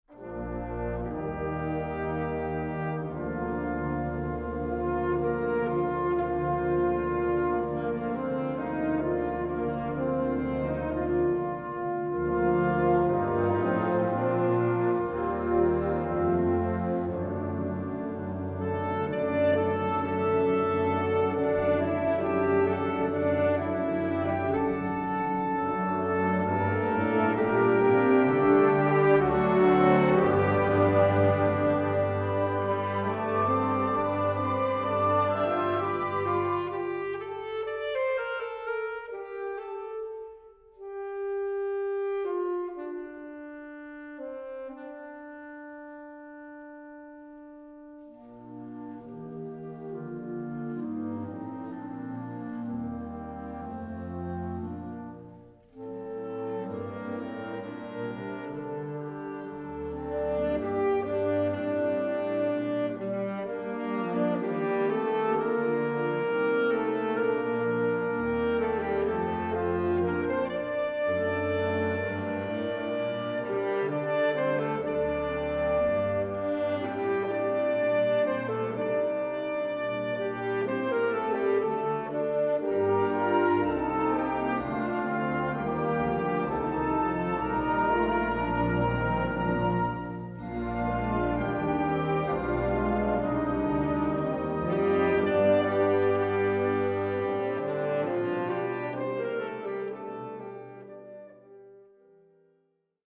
Concert Band ou Harmonie et 2 Saxophones